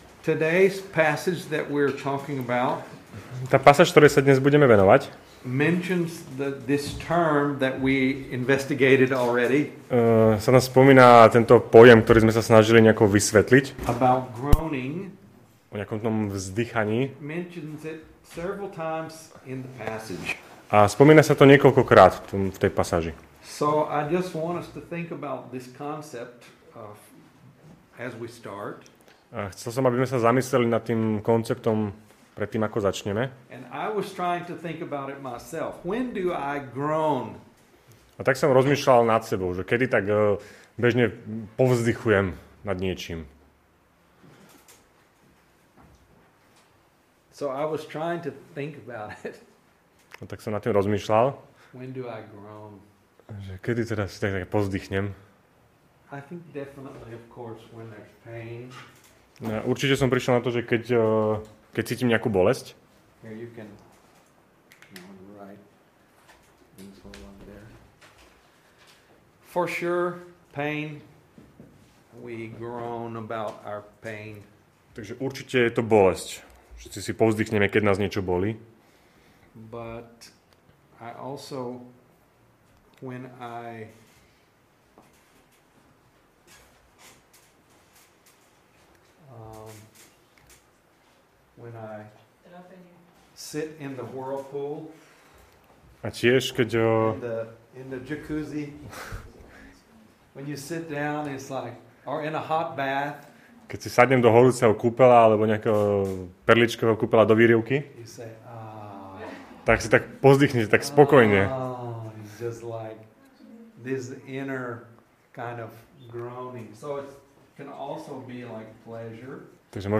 Nahrávka kázne Kresťanského centra Nový začiatok z 10. septembra 2021